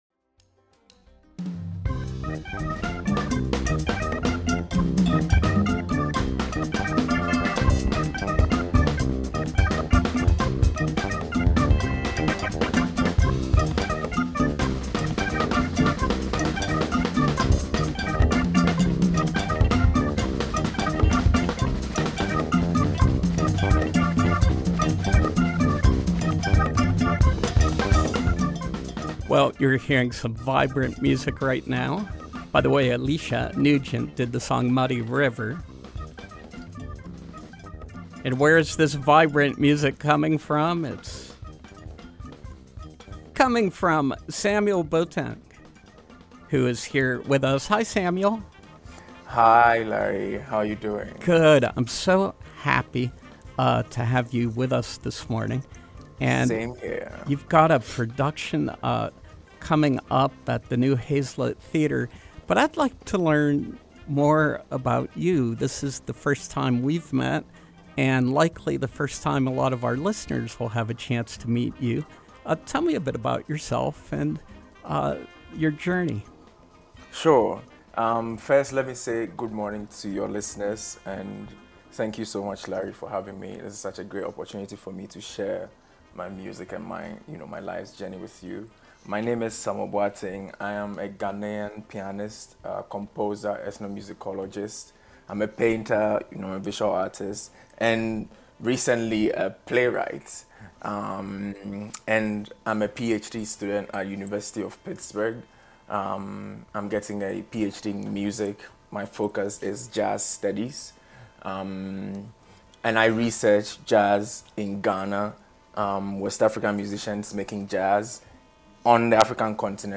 Interview: CSA Performance Series, Sunsum is Spirit